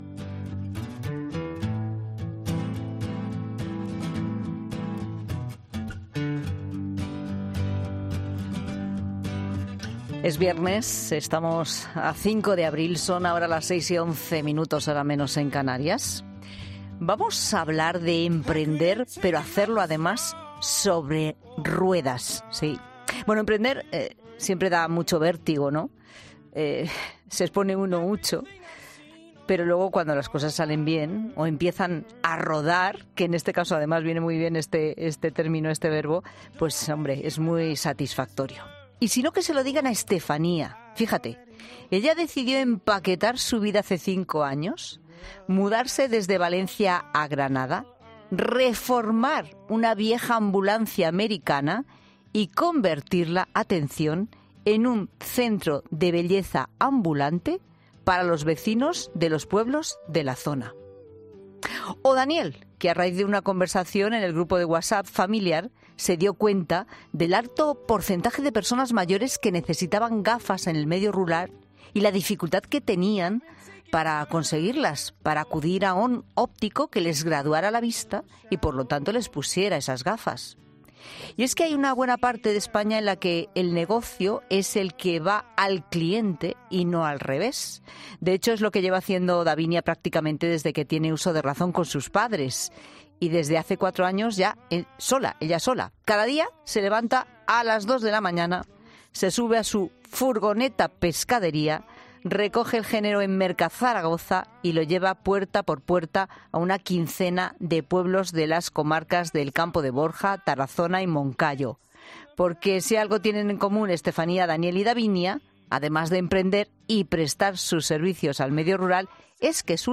Un óptico sorprende en directo al contar lo que le ha pasado minutos antes con otra entrevistada
Una coincidencia en pleno directo